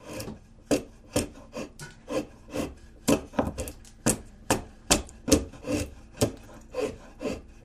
Chiseling Wood With Chisel Loop